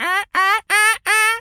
seagul_squawk_seq_04.wav